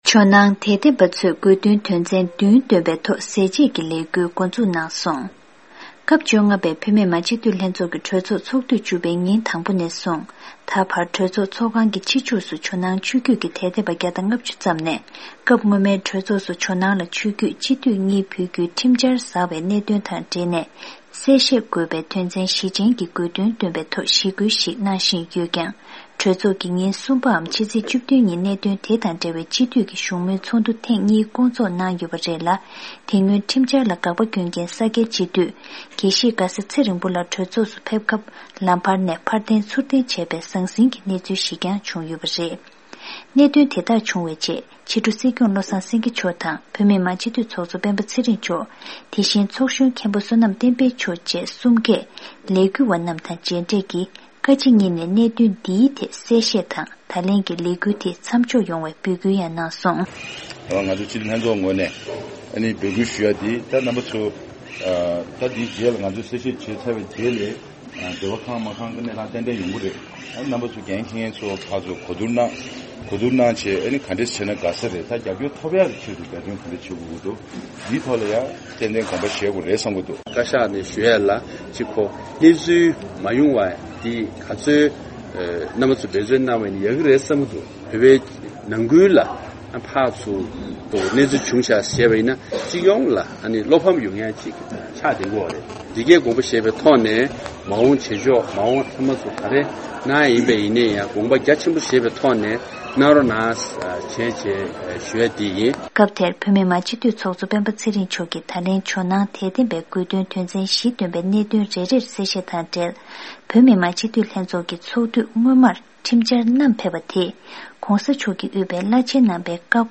གནས་ཚུལ་གསན་གྱི་རེད།